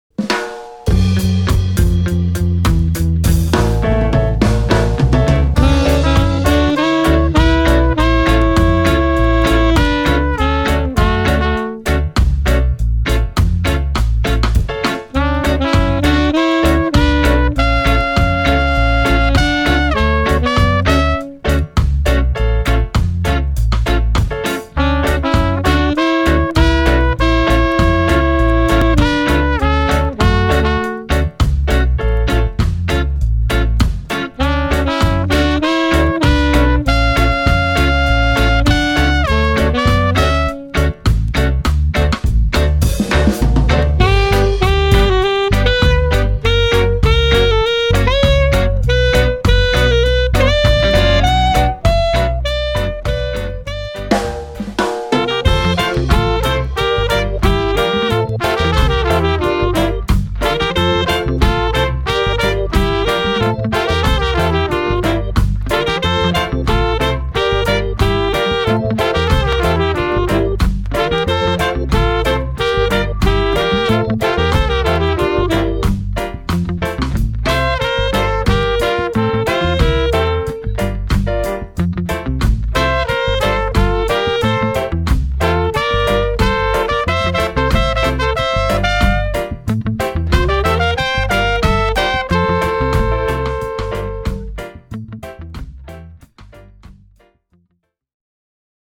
• [ Reggae/Roots Rock ]